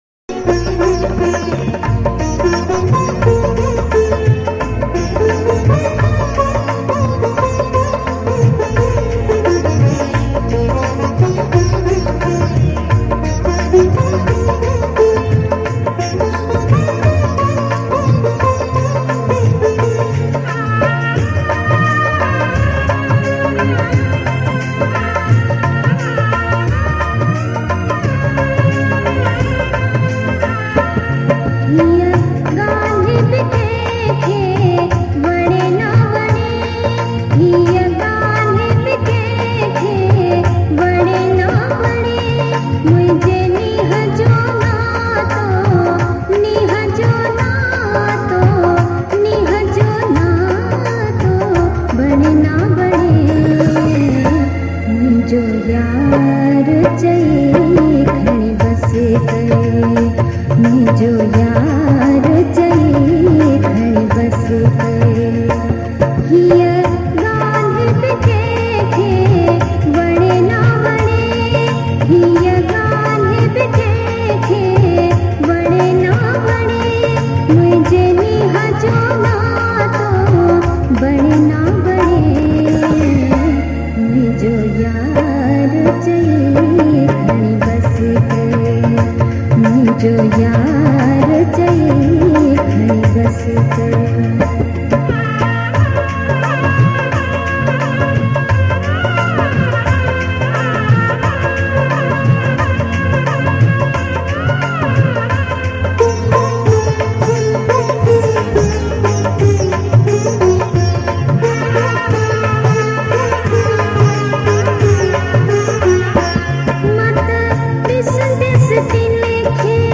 Sindhi Geet ain Kalam. Classical songs